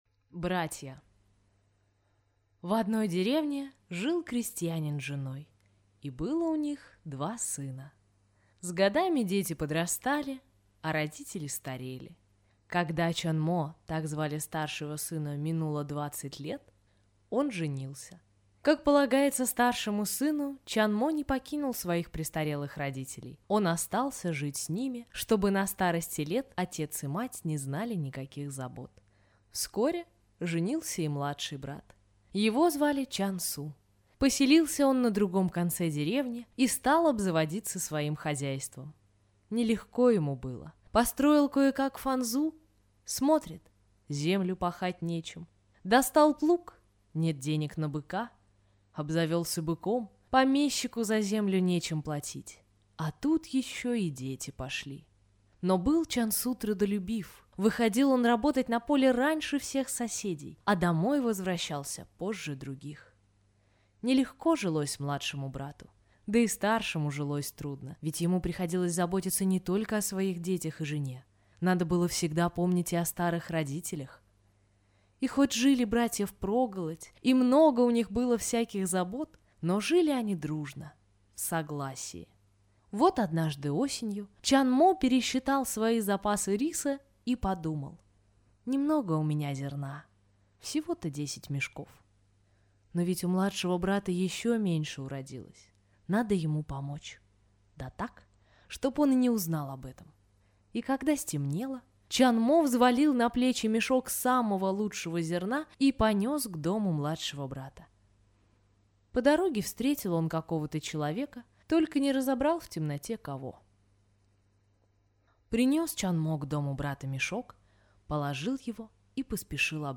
Братья – корейская аудиосказка